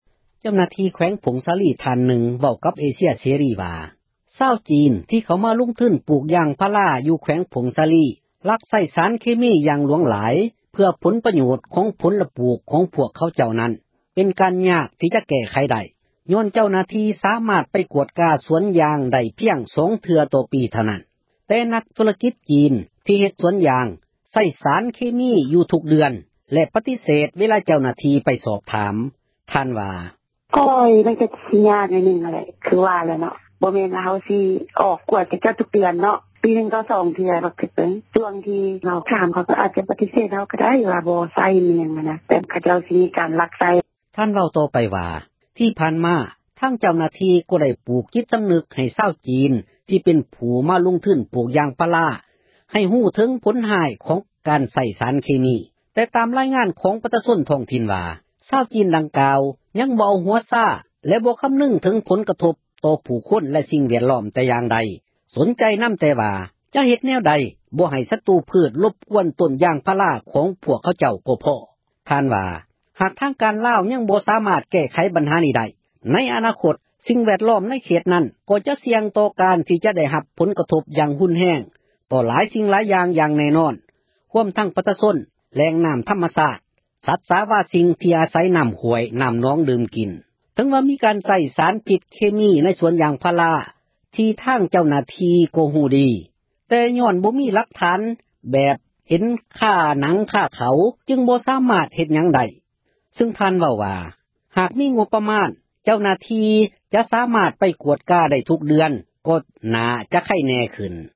ເຈົ້າໜ້າທີ່ ແຂວງ ຜົ້ງສາລີ ທ່ານນຶ່ງ ເວົ້າກັບ ເອເຊັຍເສຣີ ວ່າ ຊາວຈີນ ທີ່ເຂົ້າມາ ລົງທຶນ ປູກຢາງພາລາ ຢູ່ໃນແຂວງ ລັກໃຊ້ ສາຣເຄມີ ຢ່າງຫລວງຫລາຍ ເພື່ອຜົນປໂຍດ ຂອງ ພວກເຂົາເຈົ້ານັ້ນ ຍັງແກ້ໄຂ ບໍ່ໄດ້ ຍ້ອນເຈົ້າໜ້າທີ່ ໄປກວດກາ ສວນຢາງພາລາ ພຽງ 2 ເທື່ອຕໍ່ປີ ເທົ່ານັ້ນ ແຕ່ພວກນັກ ລົງທຶນຈີນ ໃຊ້ສາຣເຄມີ ຢູ່ທຸກເດືອນ ແລະ ກໍຍັງ ປະຕິເສດ ເວລາ ເຈົ້າໜ້າທີ່ ກວດກາ ໄປສອບຖາມ.